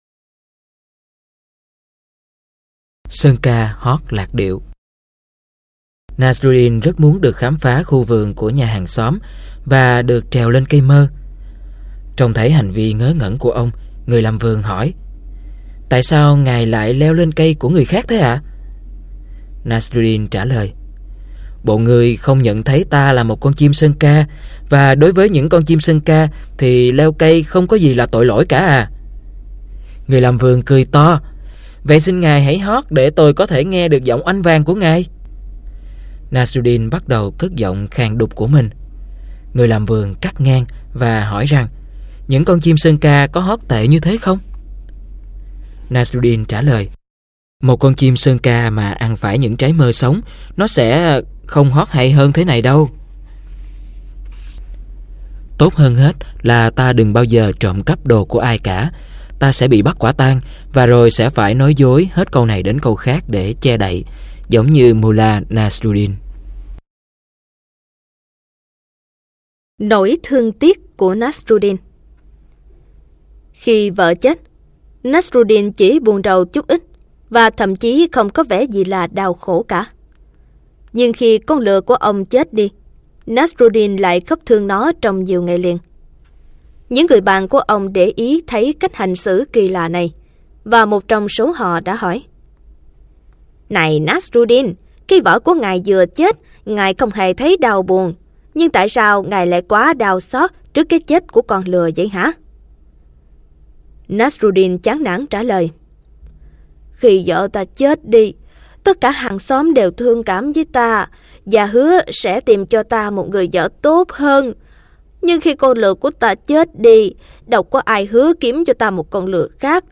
Sách nói | Truyện cười 9